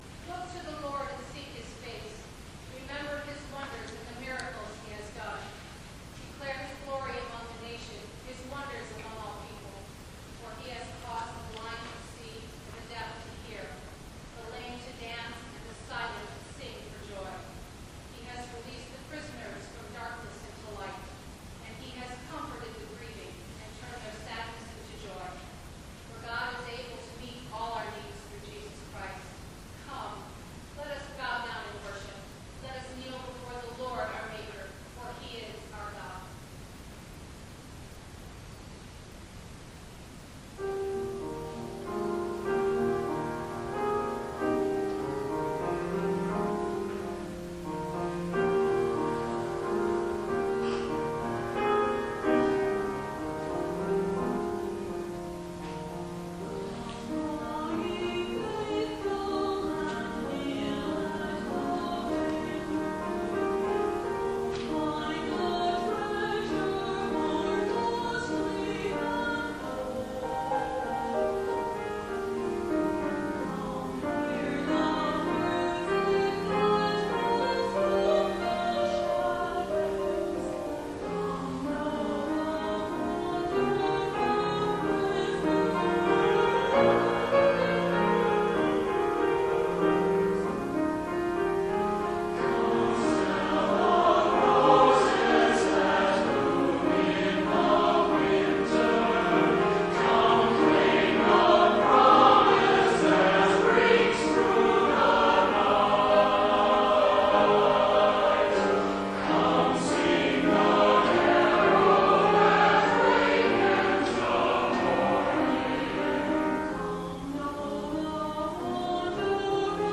Holiday Concert :: Season of Wonders